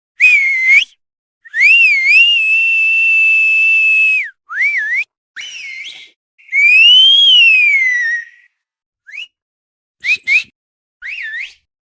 Звук свиста человека ртом
22. Звук свиста человека ртом (8 вариантов)
svist-cheloveka-8.mp3